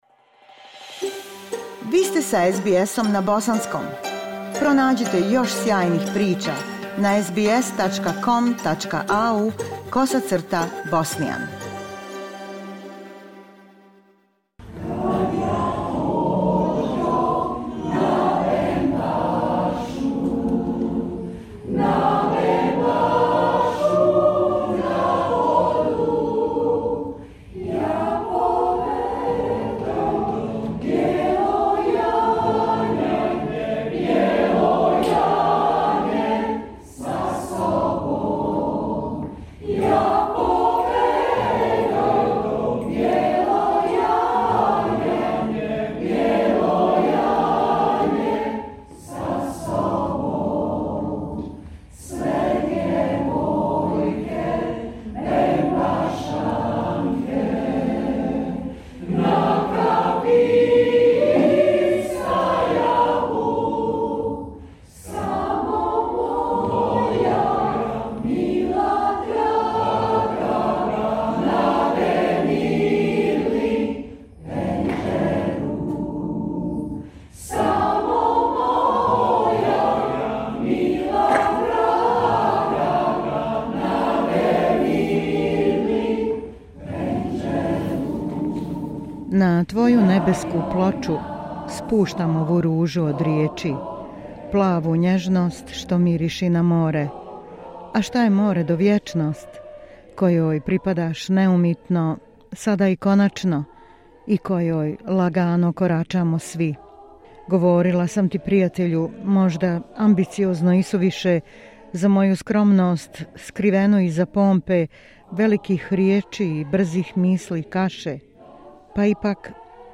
Noć je bila ispunjena i poezijom.
Uz pjesmu "Vjeruj u ljubav" svi u publici su se uhvatili za ruke.